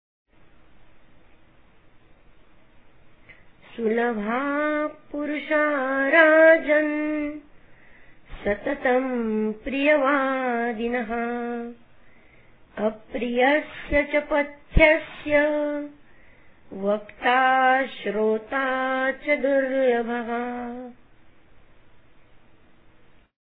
सुभाषित ऎका